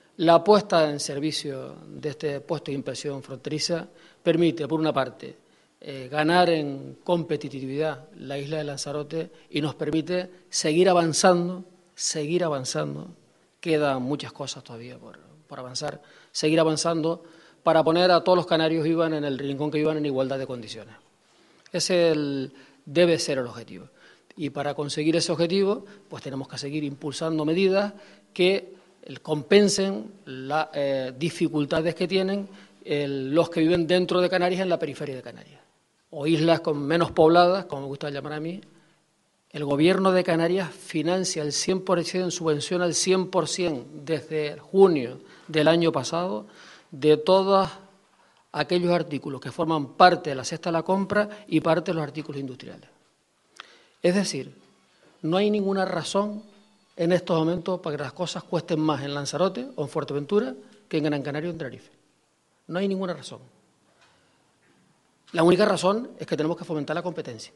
El presidente autonómico realizó estas declaraciones durante la inauguración del Puesto de Inspección Fronteriza.